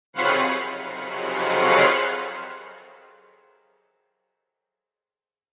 描述：悬疑戏剧电影短片经典器乐电影戏剧电影惊险幻影焦虑险恶。用Garritan ARIA播放器录制......
Tag: 快感 电影 焦虑 悬念 戏剧 戏曲 电影 险恶 电影 古典 剪辑 总之 器乐 幻影